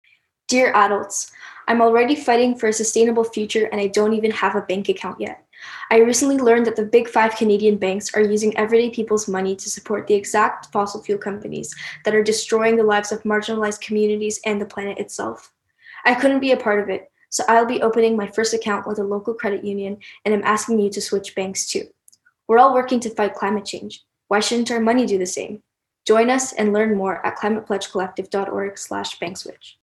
Radio and Podcast Ads – Climate Pledge Collective
Voice Actors from Artists for Real Climate Action (ARCA), Fridays for Future Toronto, and Climatestrike Canada
bank-switch-ads-youth-speech-no-background-music-audio-bump.mp3